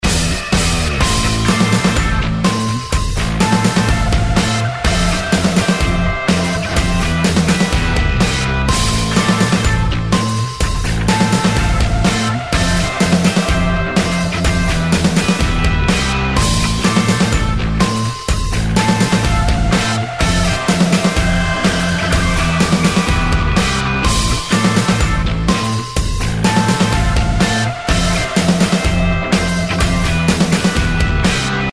Зарубежные рингтоны